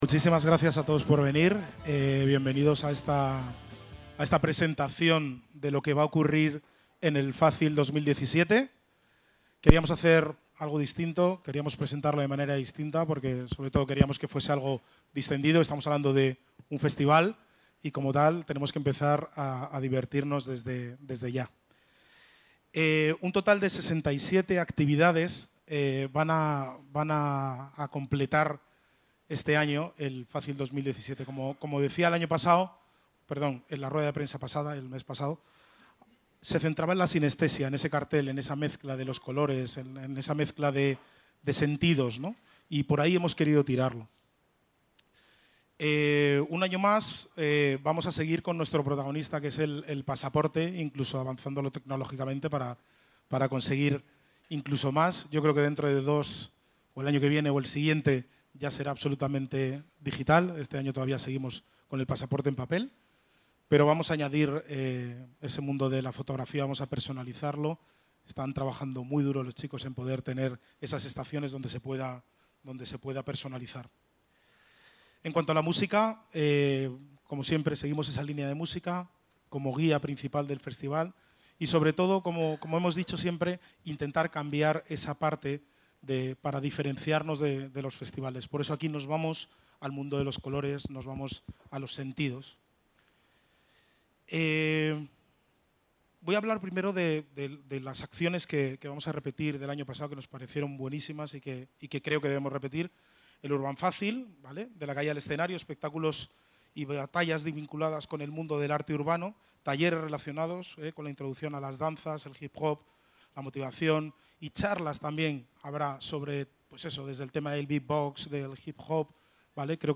Presentación FACYL.